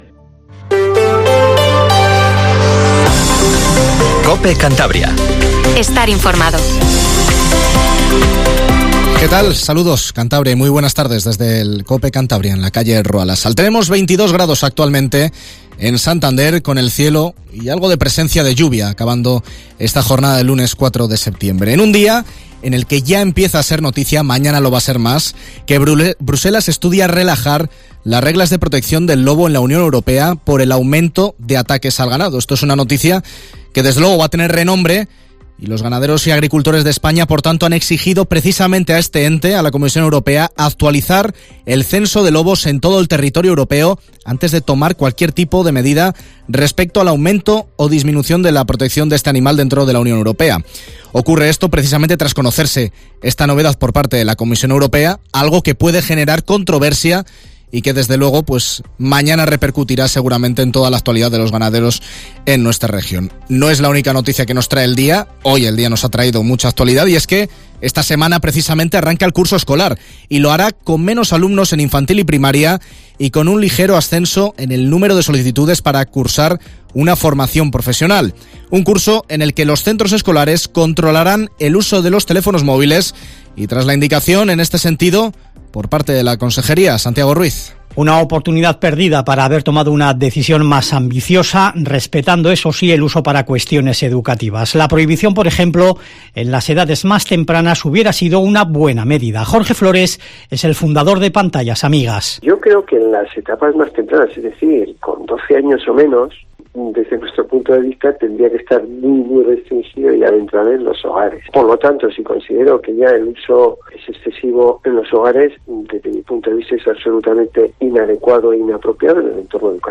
Informativo La Linterna COPE CANTABRIA 19:50